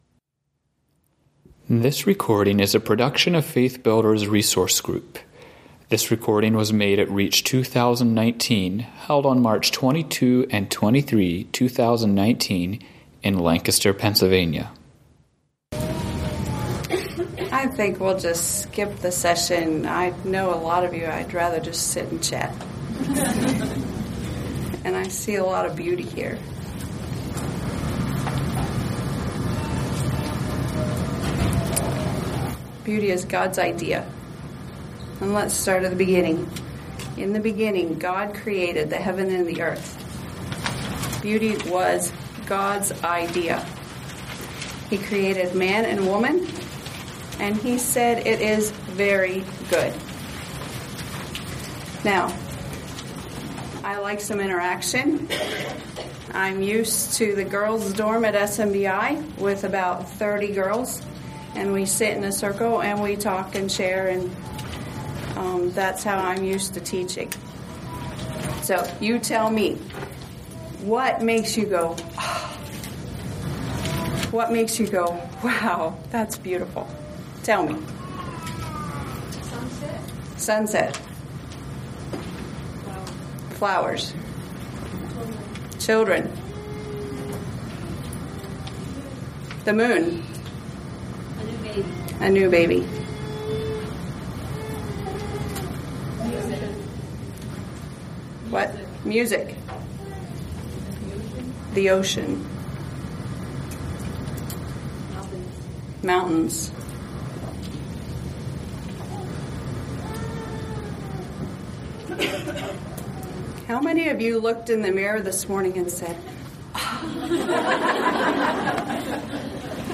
Home » Lectures » Beauty, Men, and Matriska